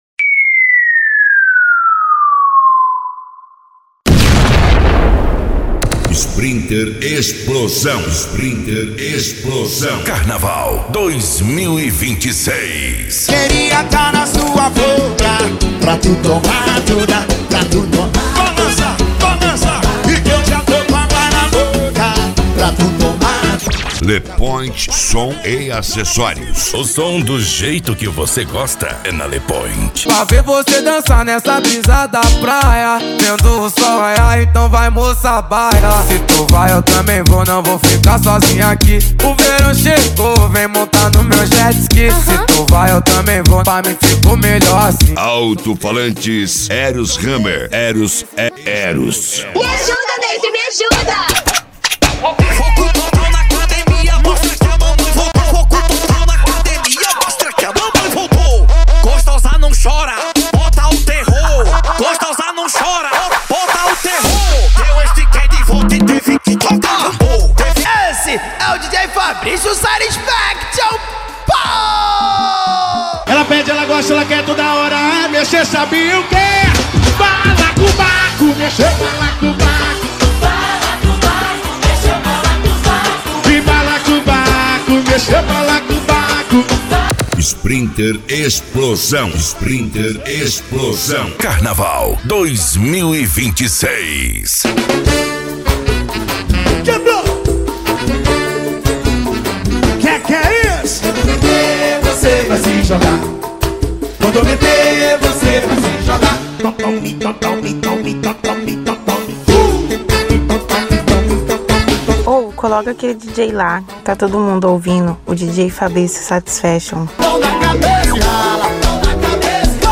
Arrocha
Funk